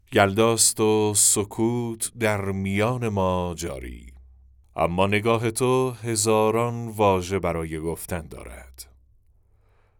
نریشن شب یلدا